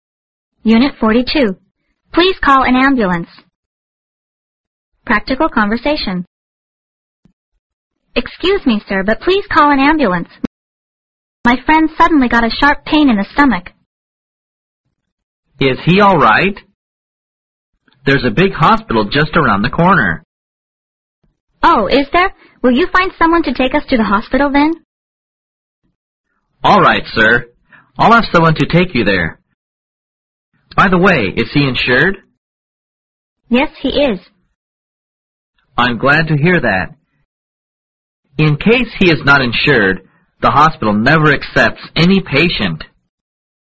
Practical conversation